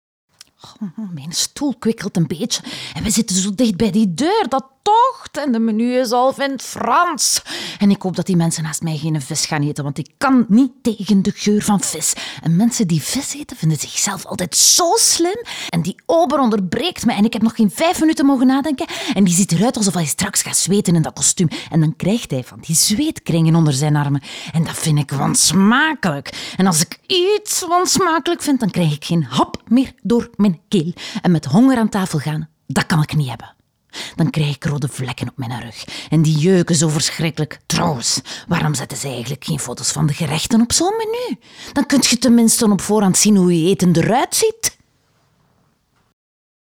Naturelle, Chaude, Douce, Accessible, Amicale